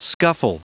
Prononciation du mot scuffle en anglais (fichier audio)
Prononciation du mot : scuffle